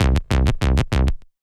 TI98BASS1 -R.wav